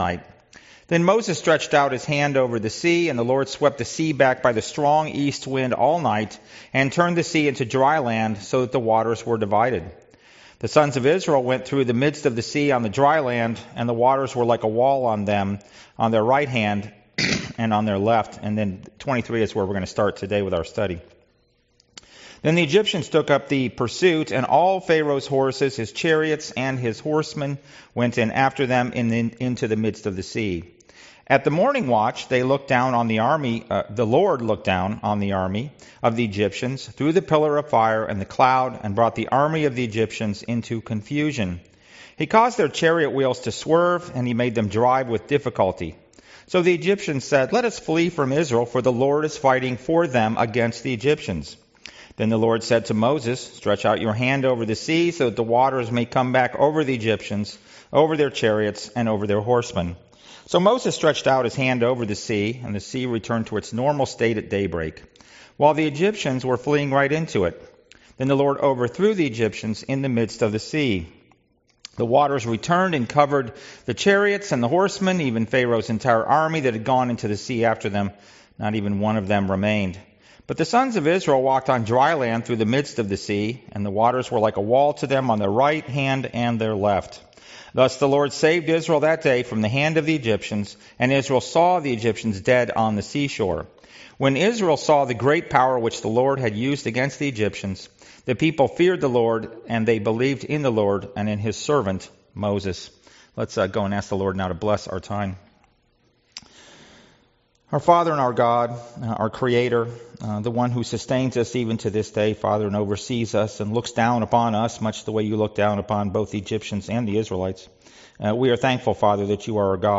Passage: Exodus 14 Service Type: Sunday School « Sorrento